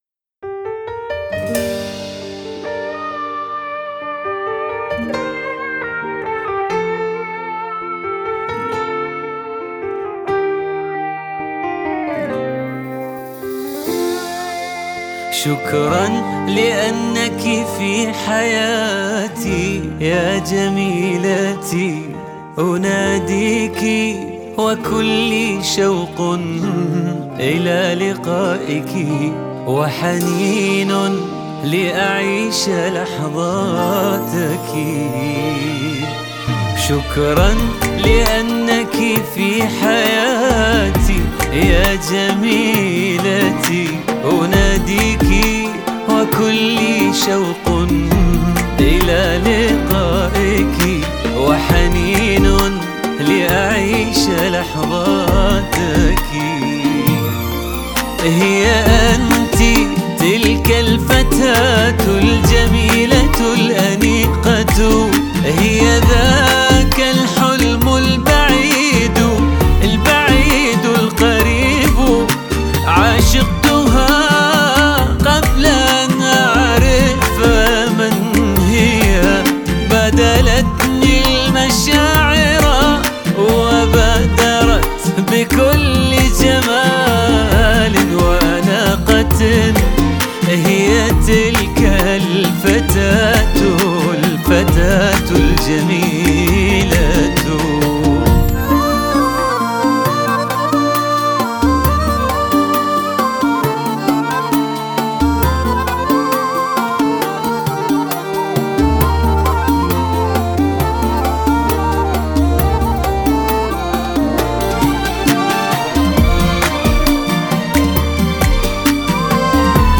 جوده عالية